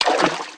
High Quality Footsteps / Water
STEPS Water, Walk 08.wav